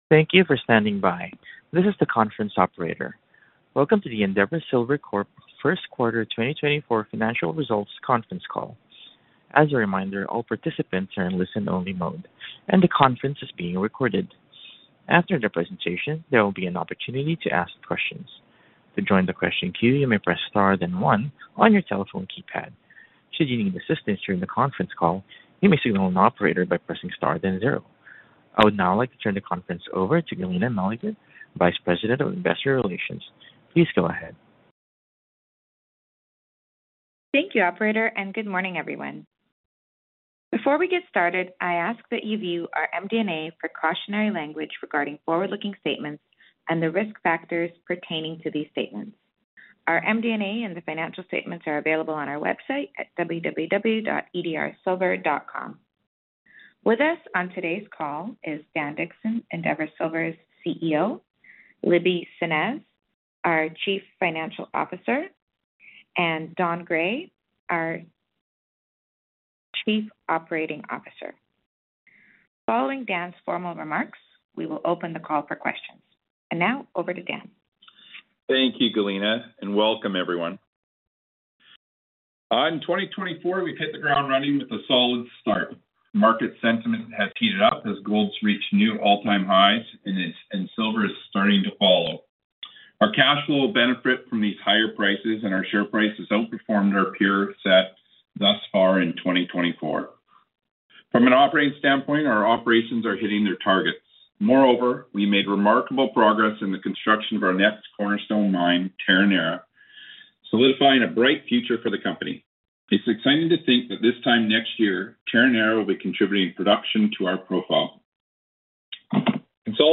First Quarter 2025 Financial Results Conference Call